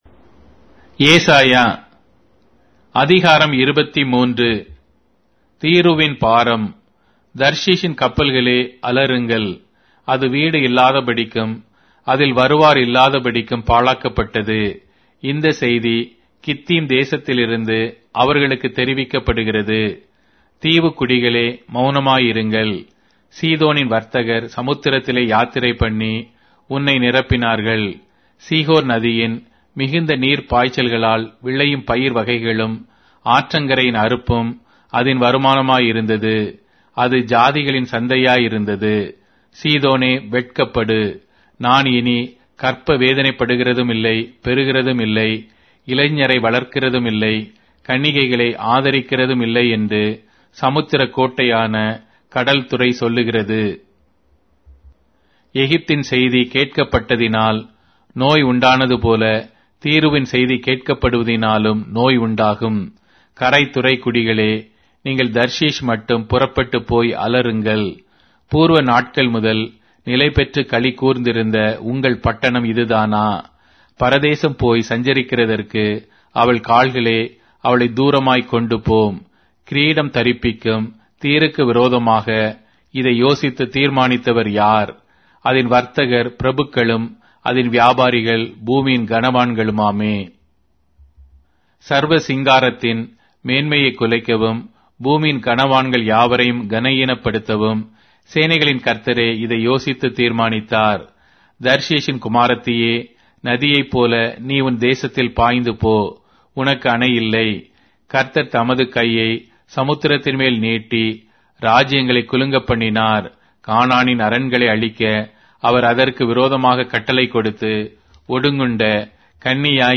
Tamil Audio Bible - Isaiah 18 in Irvas bible version